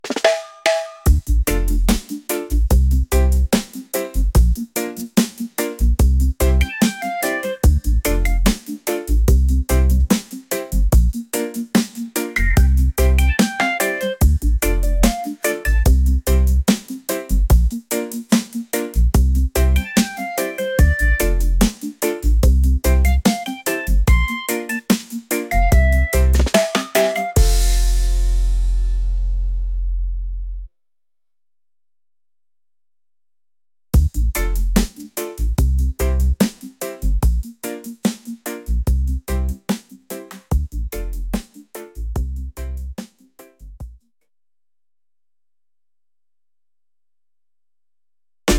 vibes | laid-back | reggae